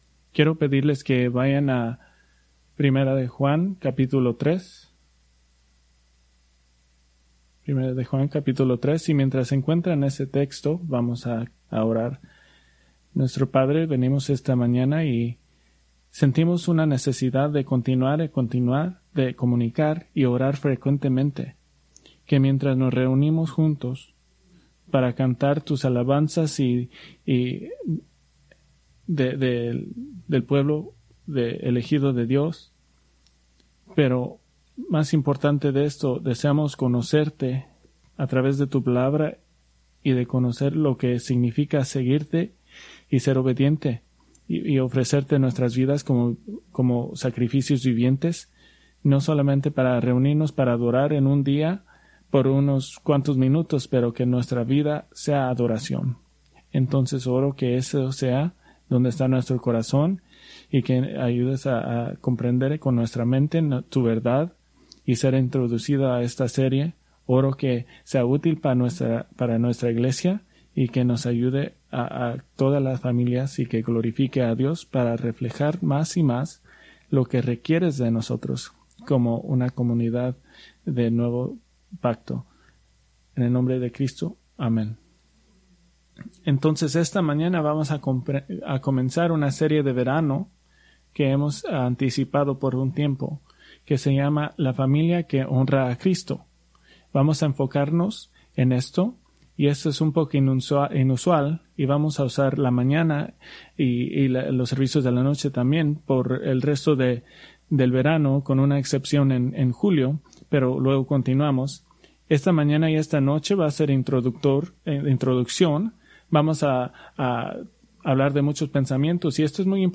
Preached June 29, 2025 from Escrituras seleccionadas